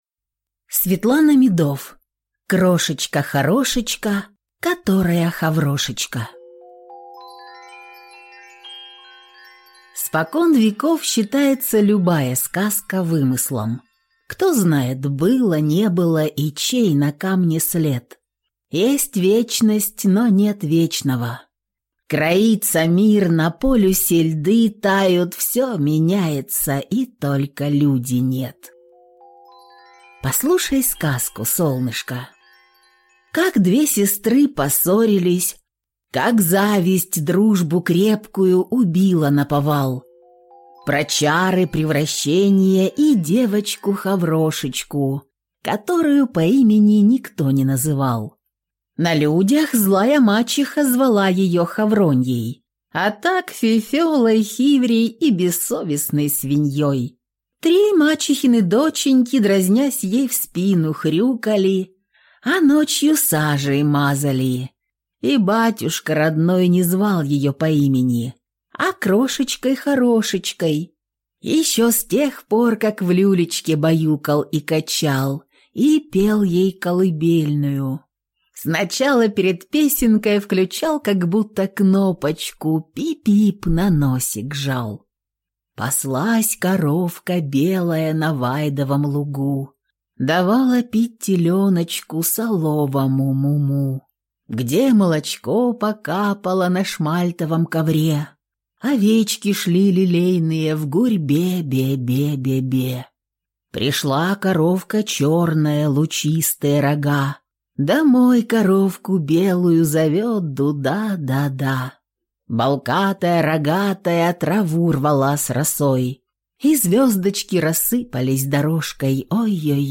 Аудиокнига Крошечка-Хорошечка, которая Хаврошечка | Библиотека аудиокниг